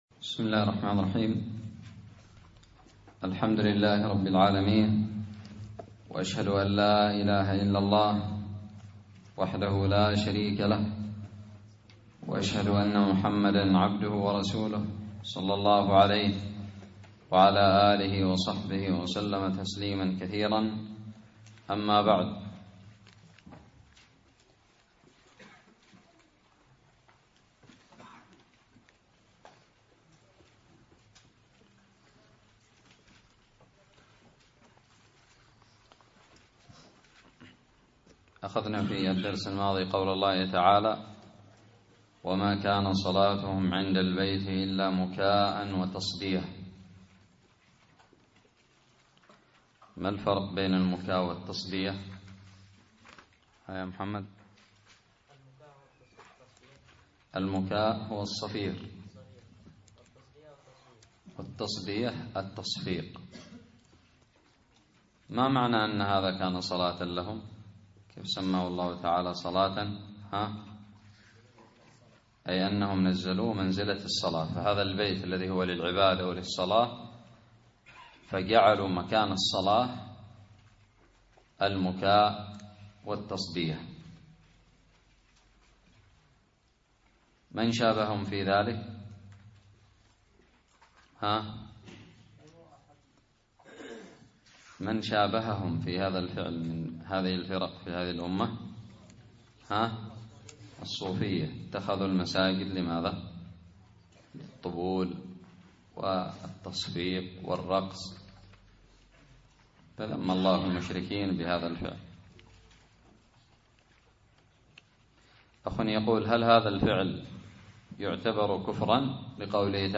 الدرس الثاثي عشر من تفسير سورة الأنفال
ألقيت بدار الحديث السلفية للعلوم الشرعية بالضالع